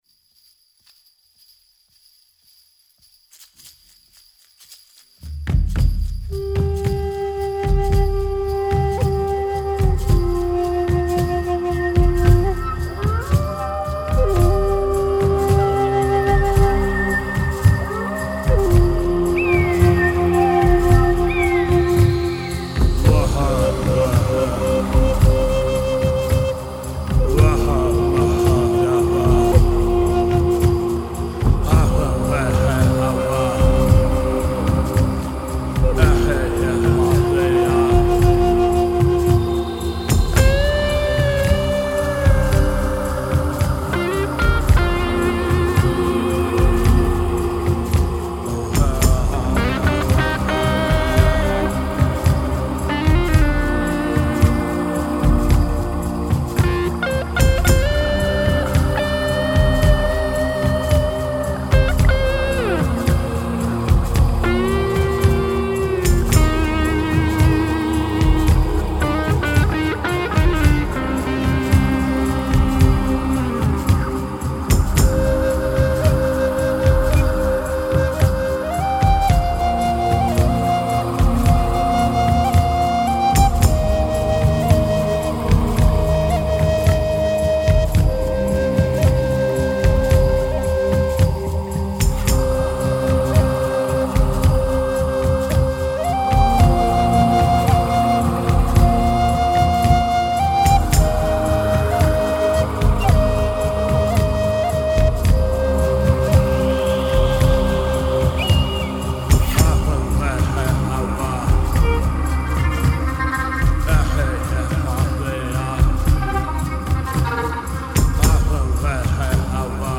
New Age Музыка шаманов Песни шаманов